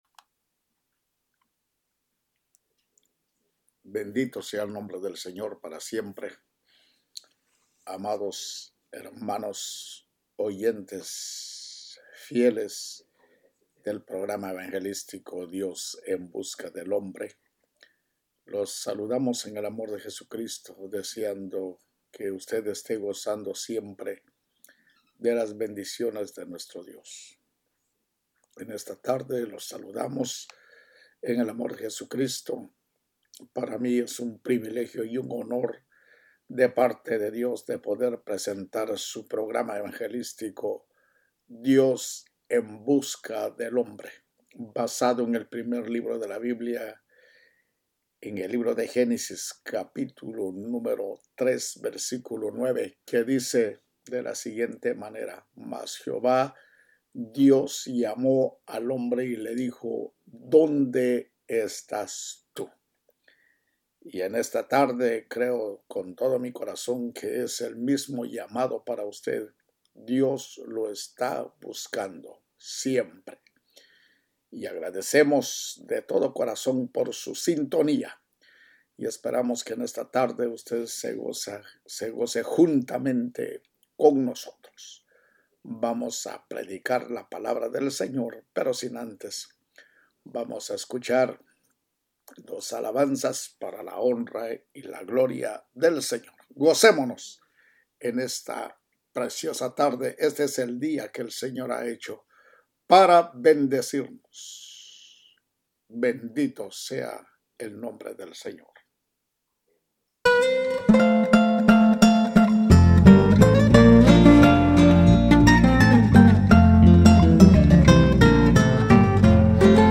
EL PELIGRO DE JUZGAR A OTROS PREDICA #4
EL-PELIGRO-DE-JUZGAR-A-OTROS-PREDICA-4mp3.mp3